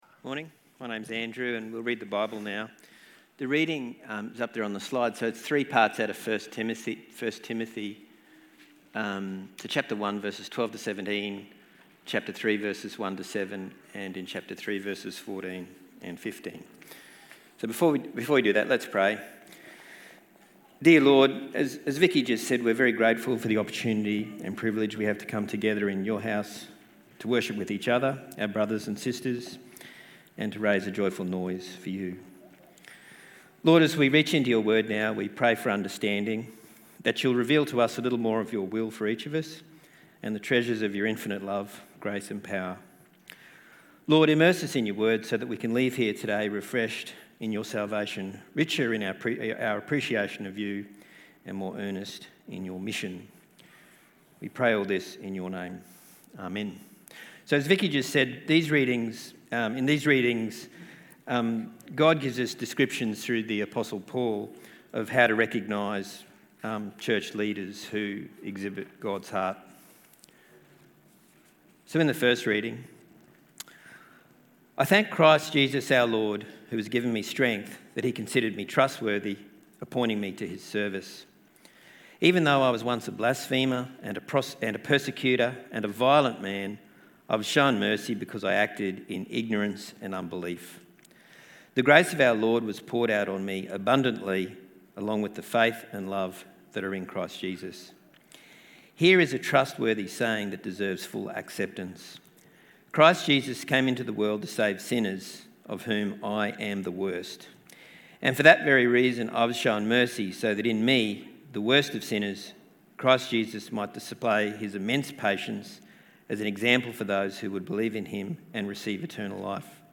Bible Reading & Talk